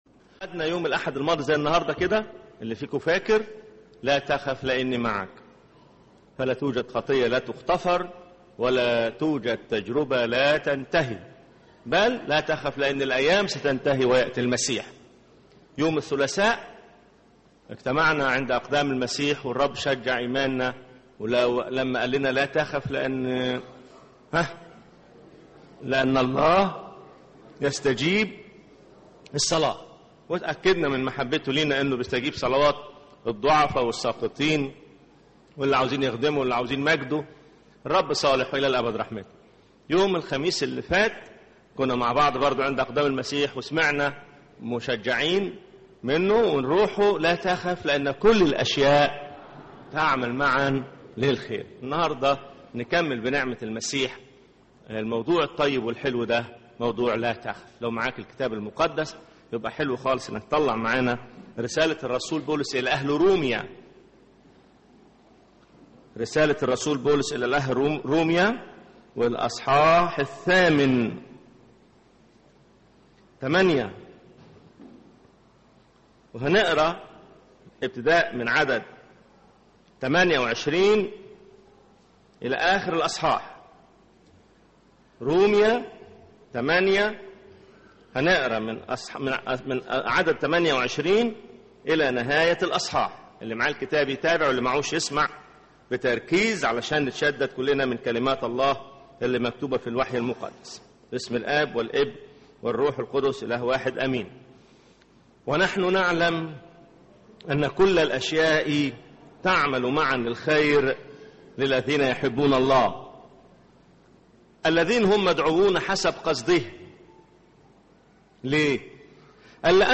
سلسلة عظات بعنوان “لا تخف”سبع عظات يواجه فيها الله خوفنا من كلمته..